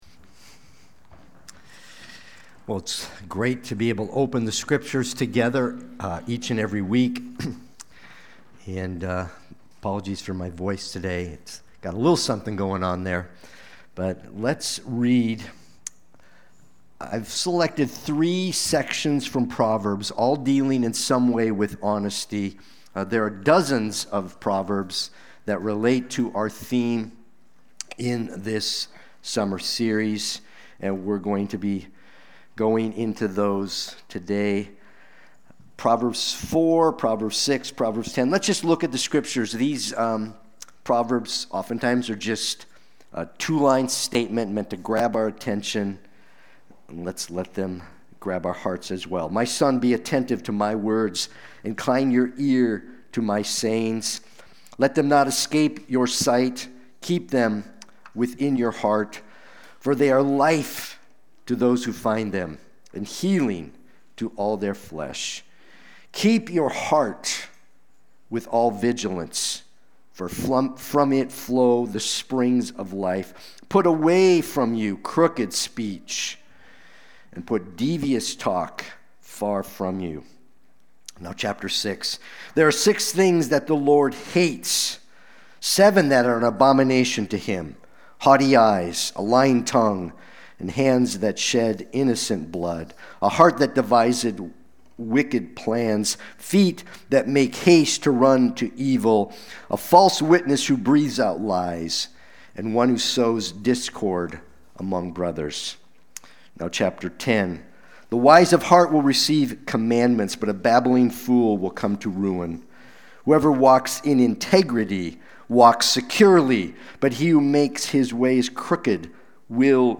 Watch the replay or listen to the sermon.